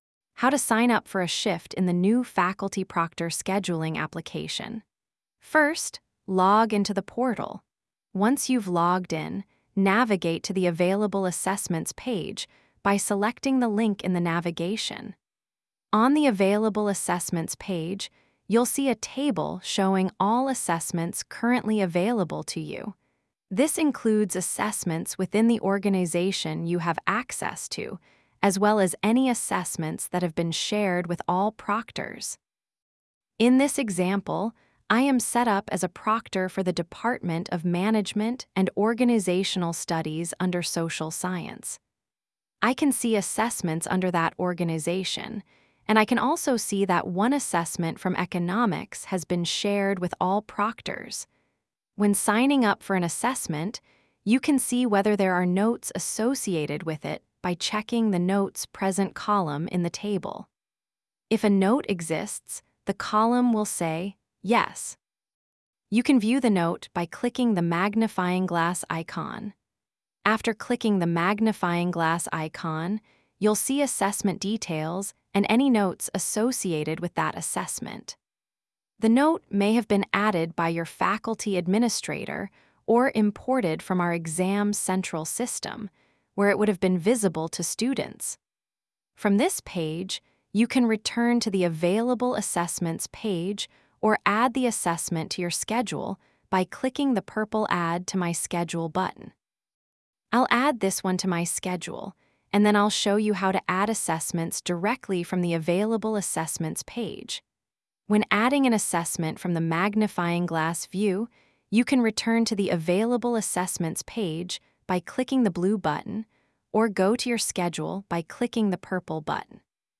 AI-TTS/script.wav